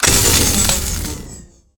overheat.ogg